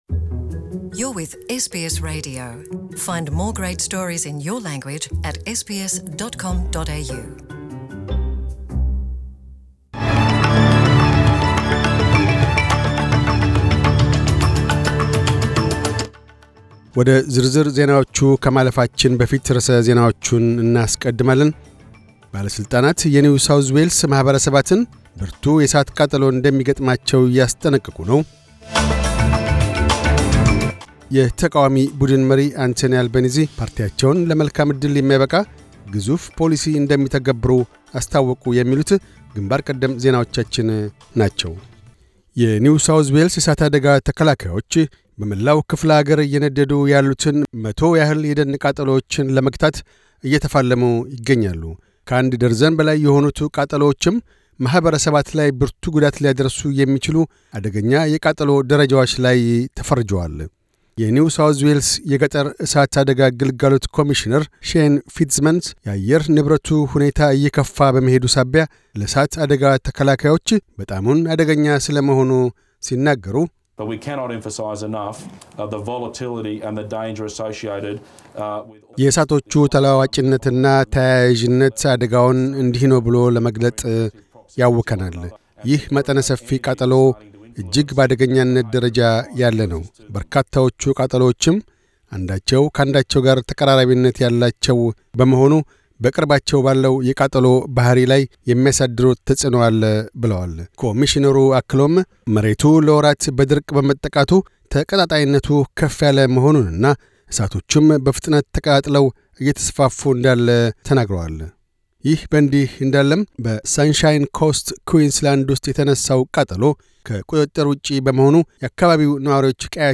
News Bulletin 0811